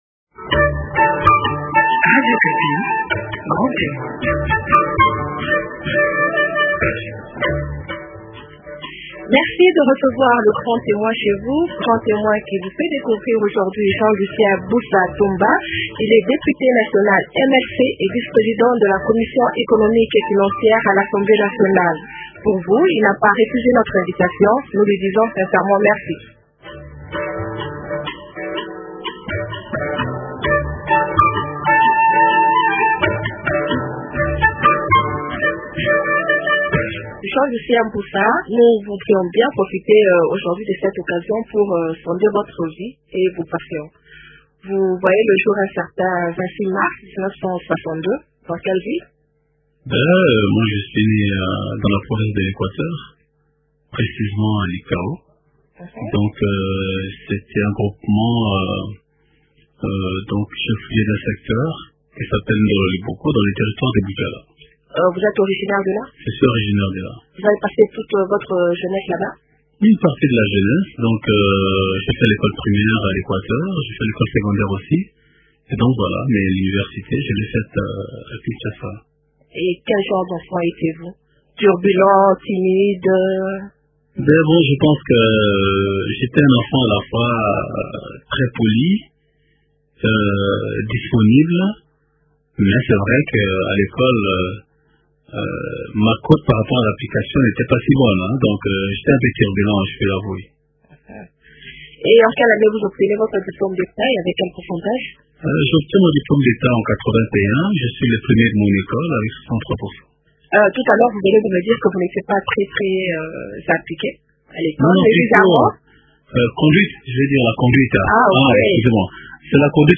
Jean Lucien Busa Ntongba est député national Mlc et vice président de la commission économique et financière à l’assemblée nationale.